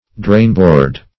drainboard \drain"board`\ n.